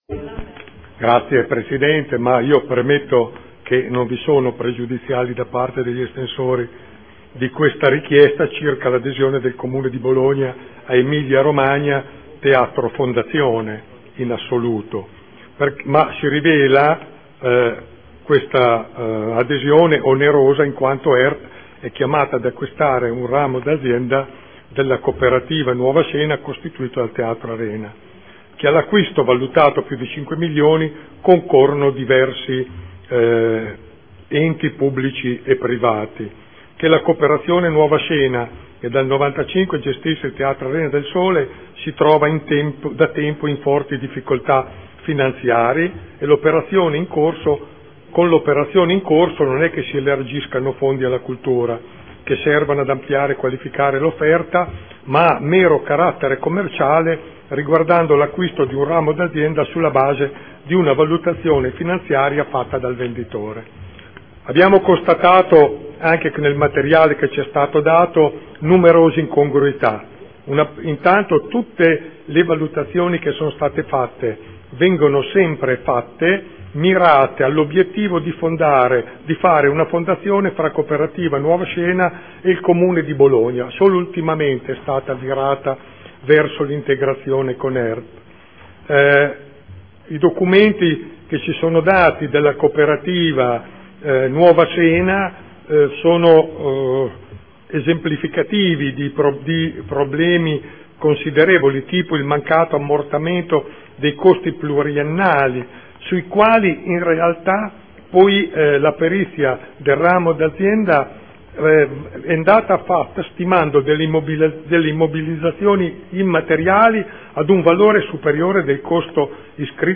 Seduta del 23/01/2014 Adesione del Comune di Bologna a Emilia Romagna Teatro Fondazione in qualità di socio fondatore necessario. Intervento a favore sospensiva.